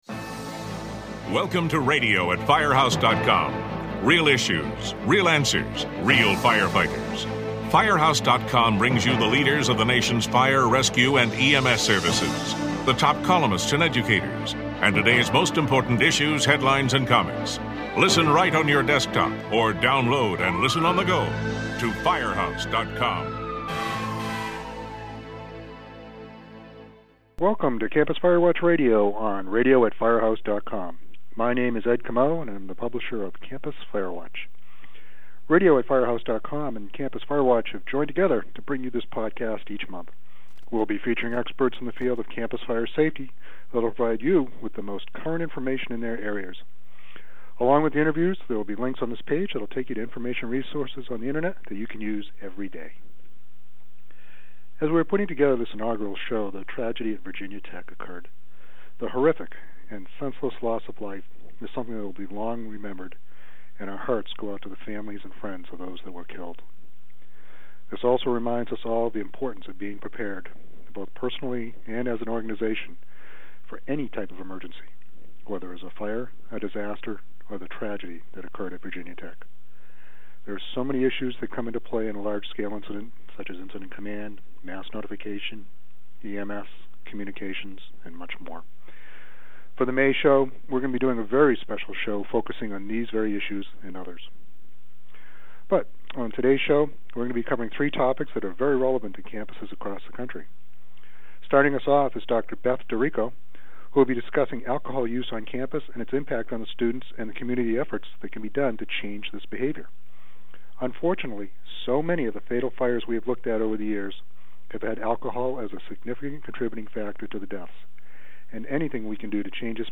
Maryland State Fire Marshal Bill Barnard discusses the timely topic of fire safe cigarettes.